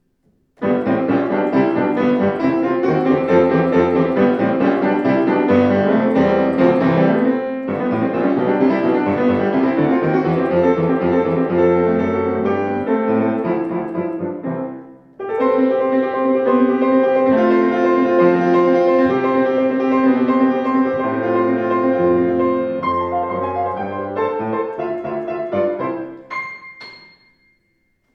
strahlender, gestaltungsfähiger Klang
Flügel